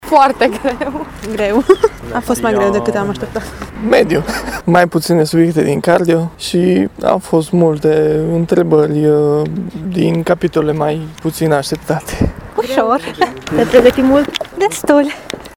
Candidaţii spun că examenul a fost greu, dar au dedicat suficient timp pregătirii: